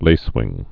(lāswĭng)